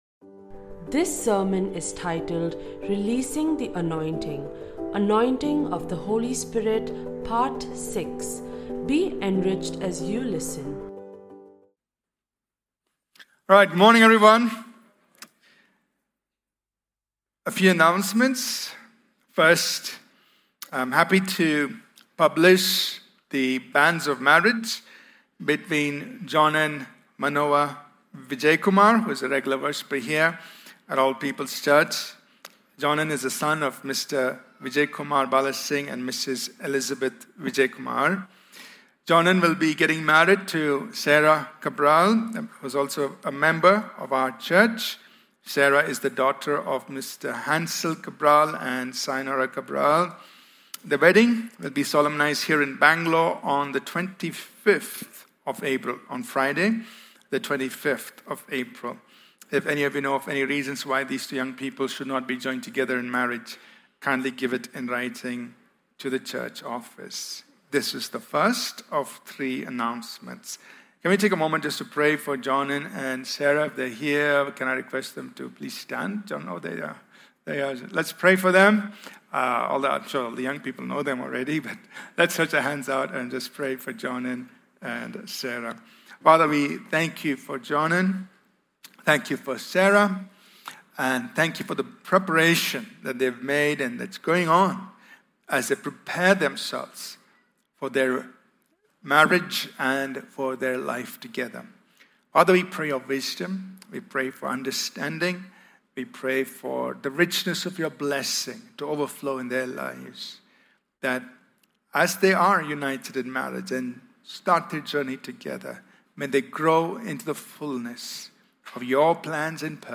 In this sermon we cover Acts chapters 13-14. These chapters record the first missionary journey of Paul and Barnabas that took place about 46-48 AD.